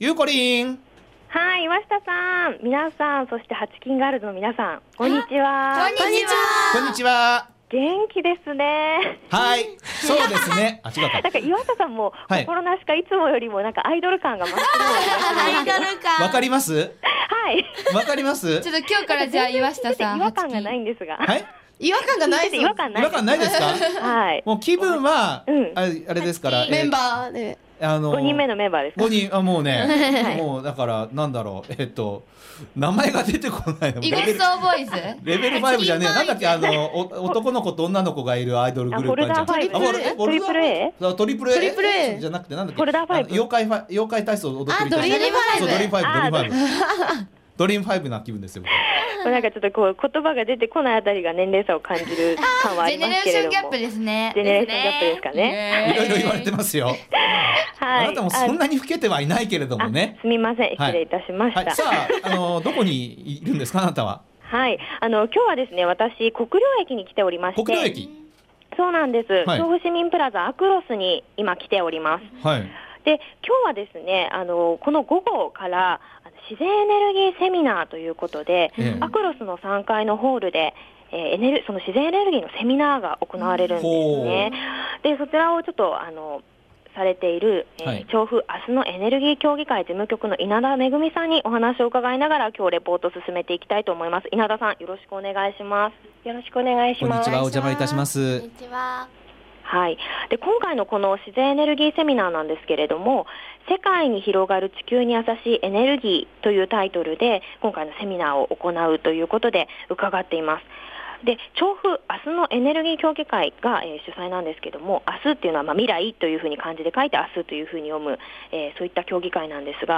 ★びゅーサン 街角レポート
こんにちは〜！今日の街角レポートは、国領駅前あくろすで開催の「自然エネルギーセミナー」の取材に行ってきました☆